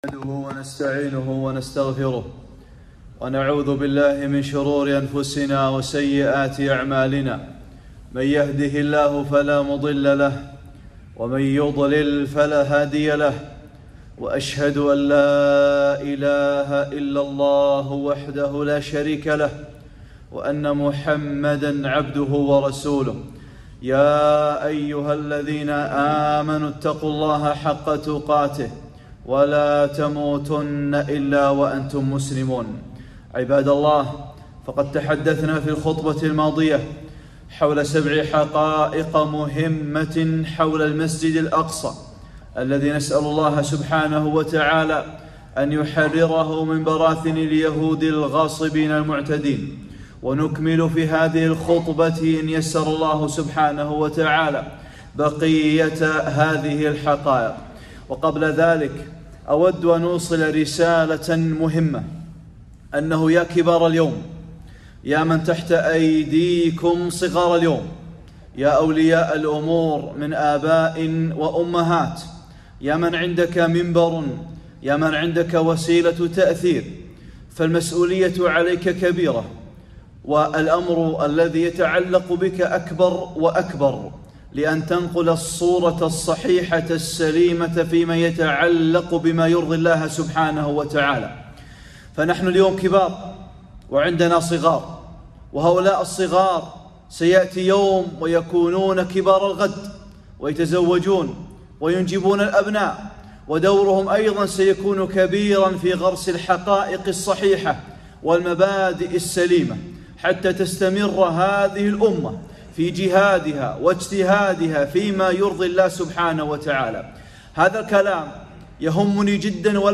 خطبة - (٢) ختام ، حقائق حول المسجد الأقصى